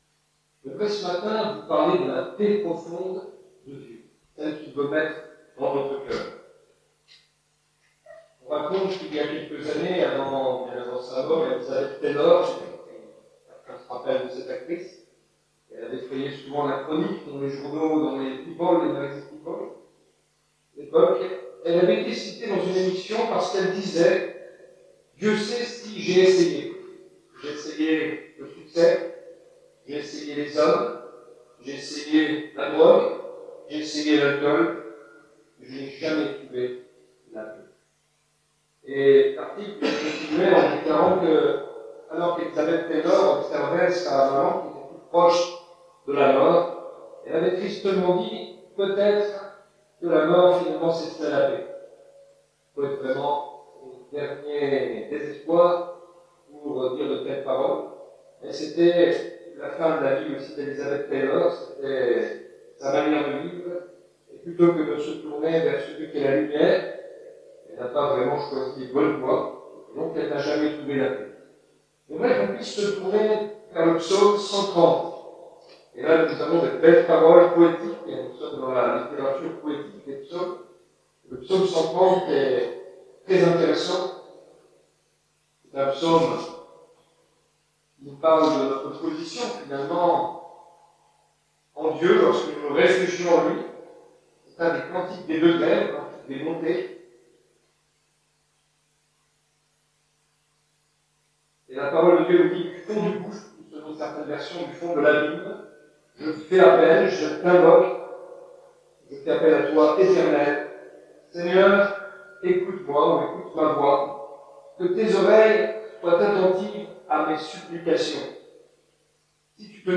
Suite à un problème lors de l'enregistrement, la qualité de l'audio est mauvaise jusque 13:50 !